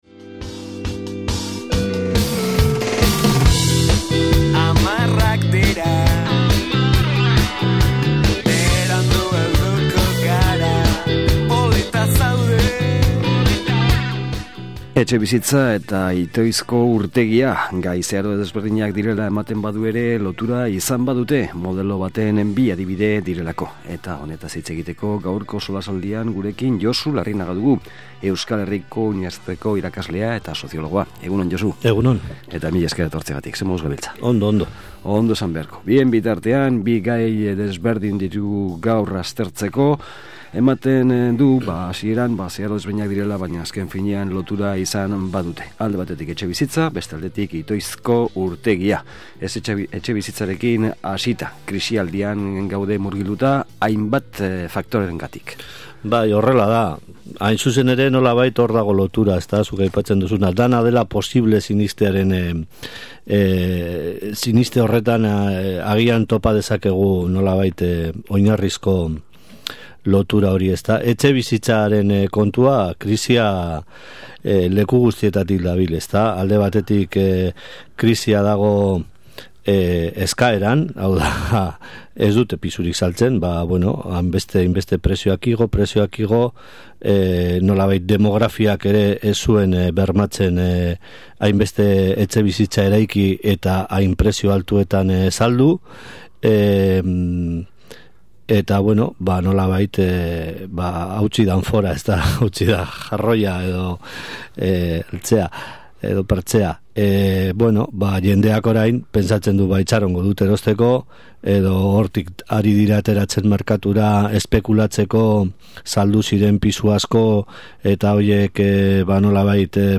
SOLASALDIA: Etxebizitza eta krisia